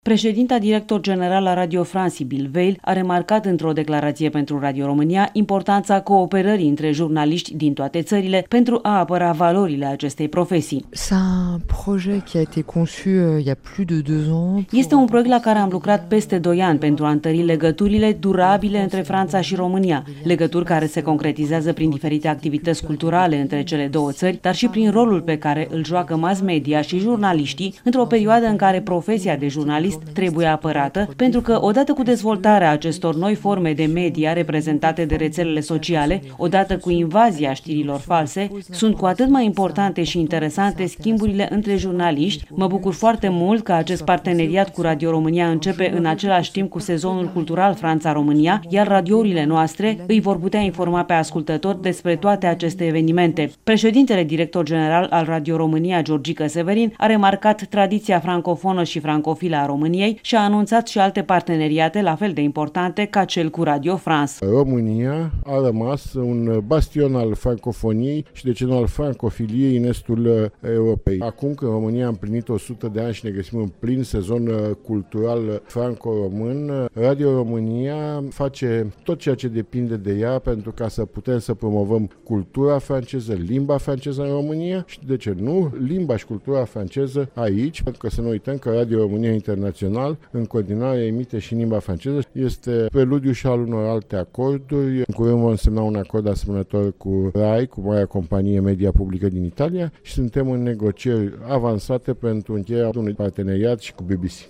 Corespondenta RRA